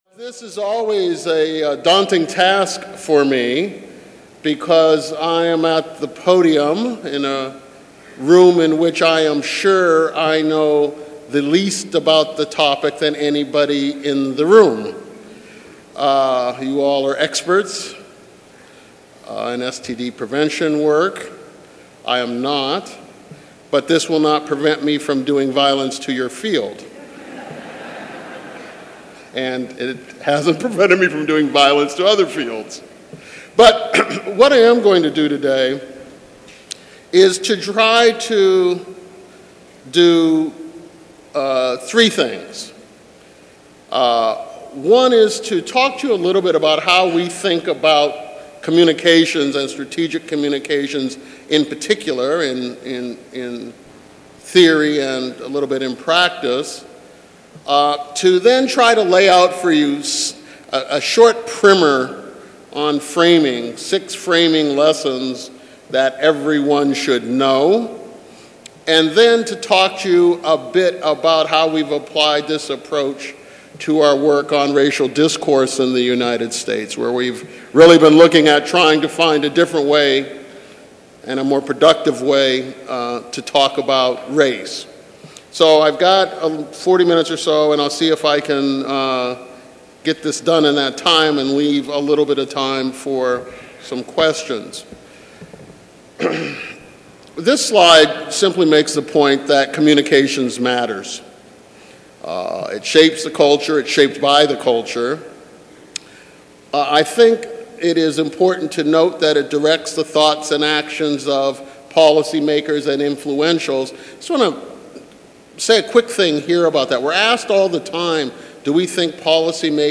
CA Audio File Recorded presentation Text Not Available.